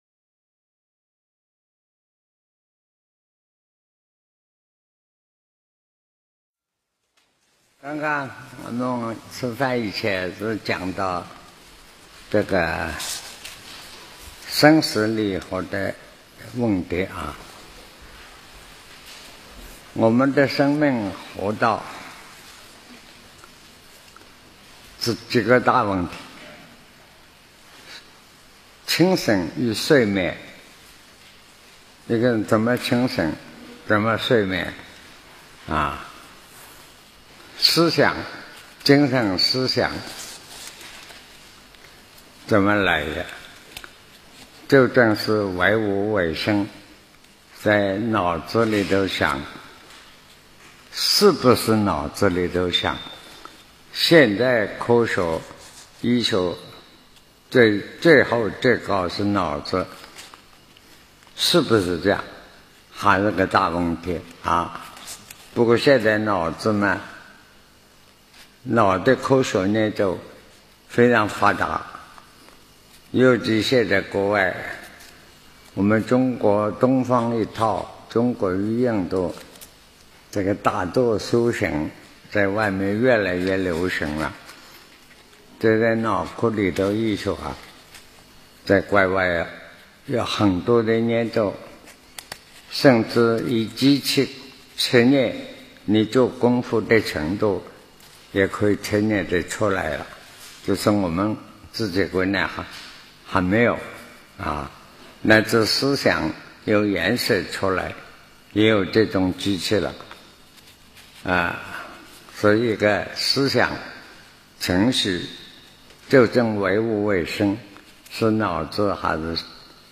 南怀瑾先生讲《黄帝内经》11讲